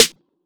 TRPPSS_SNR.wav